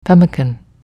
pemmican pronunciation only